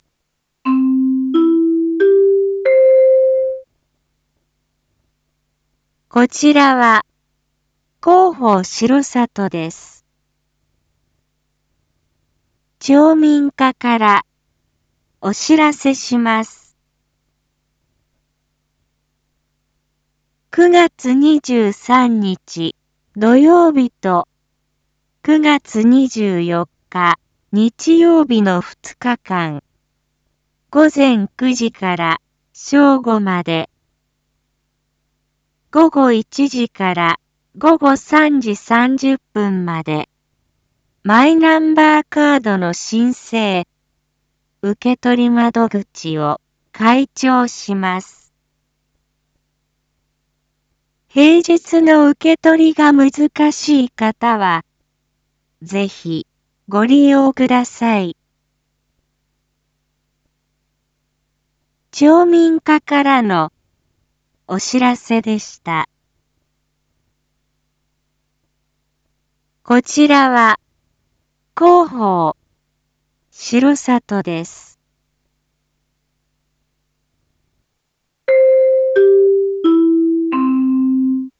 一般放送情報
Back Home 一般放送情報 音声放送 再生 一般放送情報 登録日時：2023-09-22 19:01:22 タイトル：マイナンバーカード インフォメーション：こちらは、広報しろさとです。